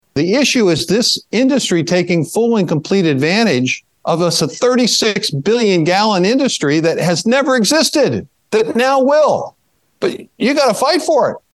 (NATIONAL ASSOCIATION OF FARM BROADCASTING)- US Agriculture Secretary Tom Vilsack told attendees of Growth Energy’s annual Hill Summit (Sept. 11-14, 2023), it’s a “make or break moment” for the biofuels industry when it comes to Sustainable Aviation Fuel.